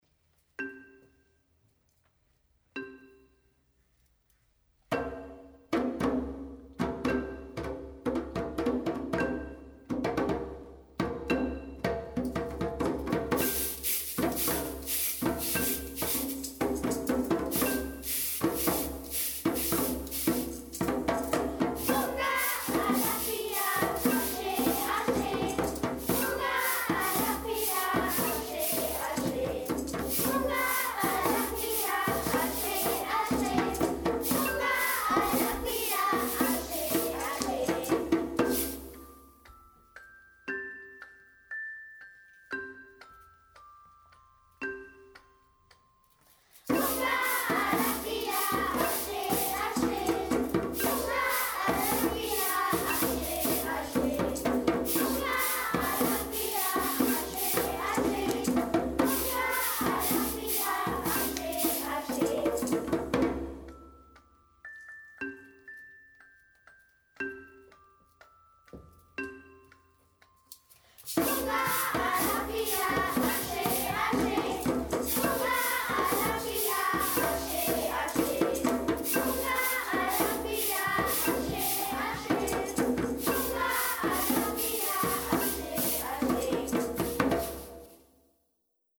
M/T: traditionell, aus Ghana Funga Alafia - Material aus der Broschüre Funga Alafia - Liedeinspielung Die Materialien dürfen zur Verwendung in Kitas und Schulen heruntergeladen und vervielfältigt werden.